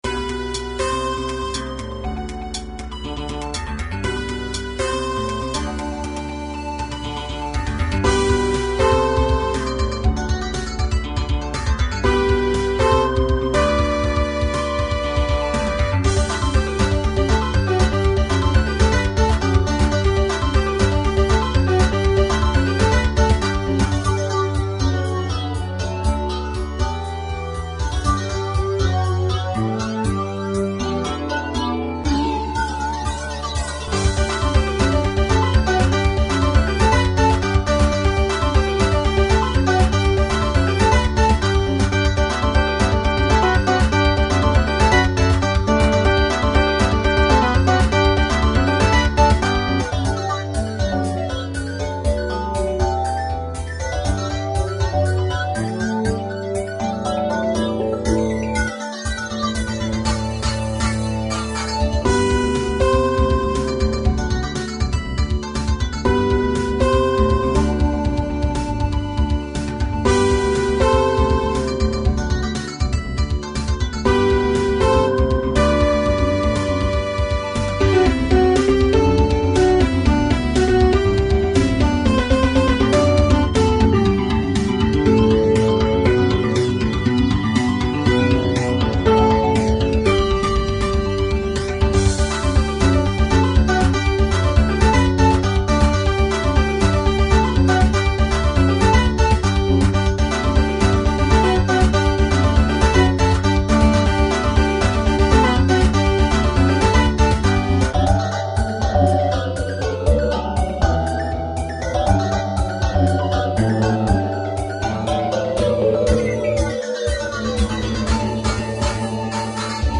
BPM=120 GENRE=TECHNO
POINT やっぱりAメロに思いっきりピコピコ入れたのがポイントでしょう。
あとは、楽器でいうならギターが良いとこ取りしてますかな。
明るい 速い